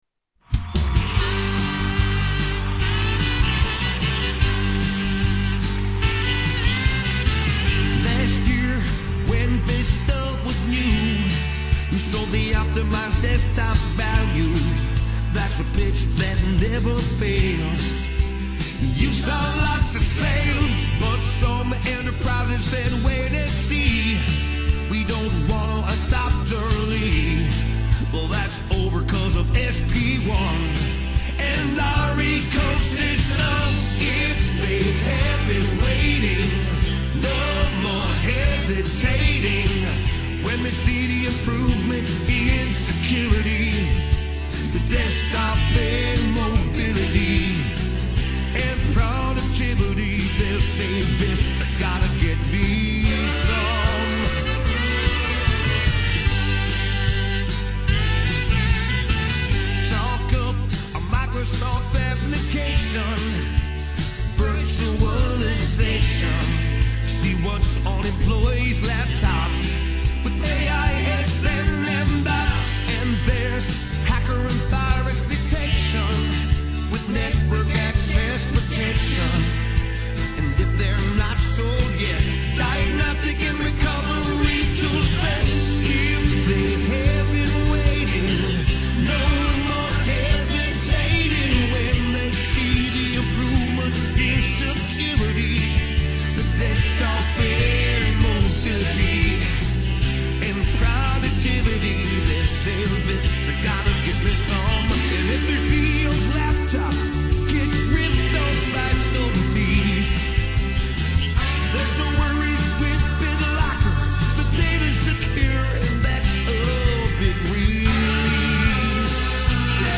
Tags: Television Awful Commericals Bad Commercials Commercials Funny